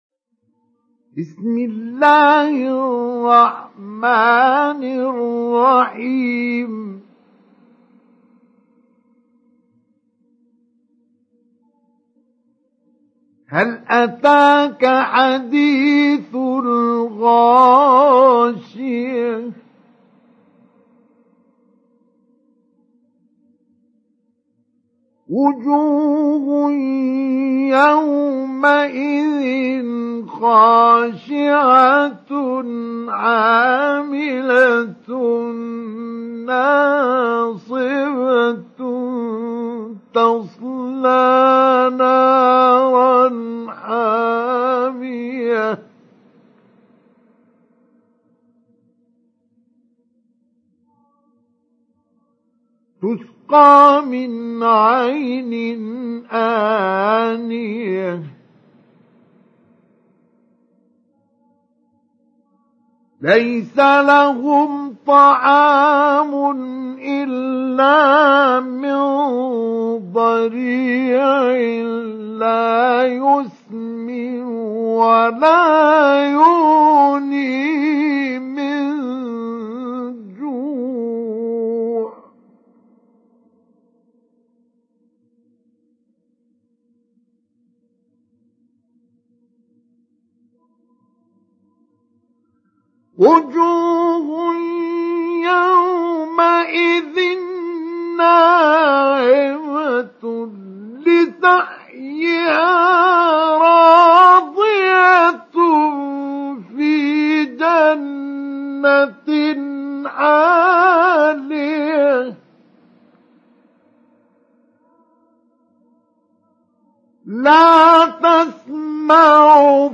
تحميل سورة الغاشية mp3 بصوت مصطفى إسماعيل مجود برواية حفص عن عاصم, تحميل استماع القرآن الكريم على الجوال mp3 كاملا بروابط مباشرة وسريعة
تحميل سورة الغاشية مصطفى إسماعيل مجود